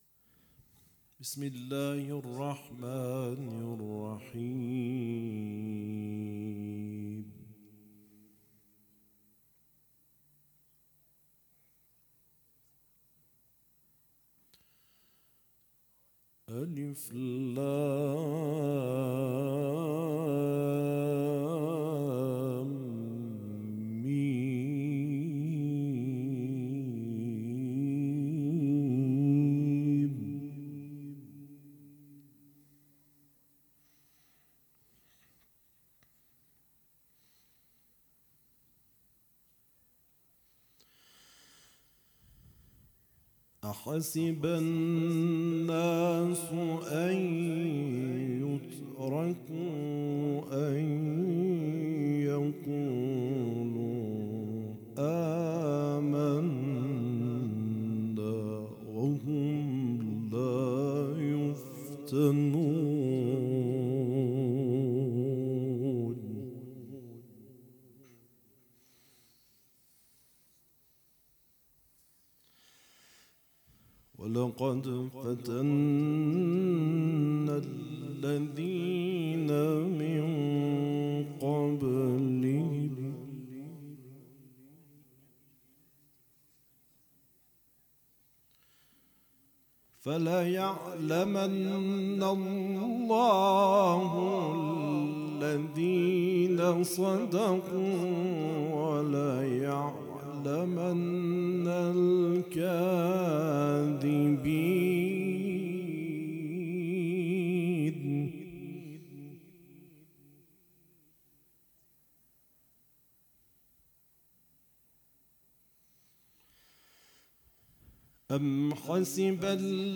صوت | تلاوت قاری ممتاز چهارمحال‌وبختیاری از سوره «عنکبوت»
برچسب ها: تلاوت قرآن ، صوت تلاوت ، سوره عنکبوت ، هیئت عزاداری